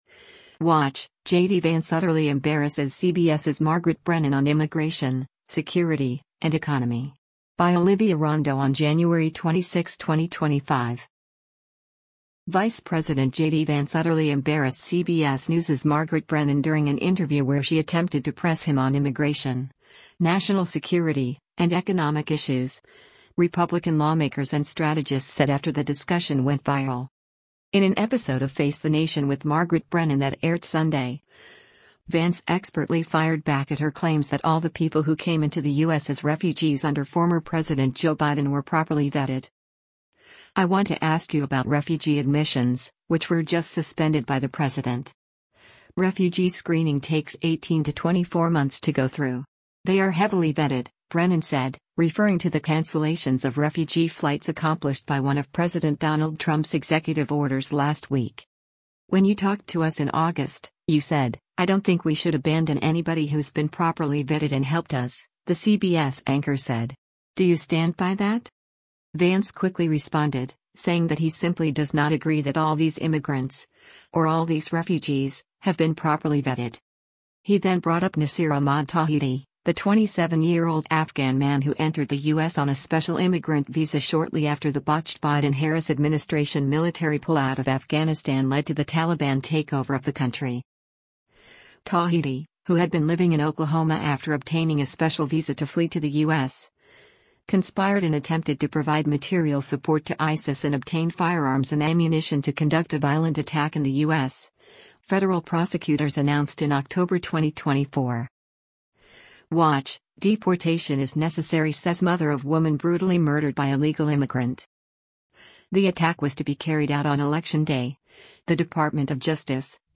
Vice President JD Vance “utterly embarrassed” CBS News’s Margaret Brennan during an interview where she attempted to press him on immigration, national security, and economic issues, Republican lawmakers and strategists said after the discussion went viral.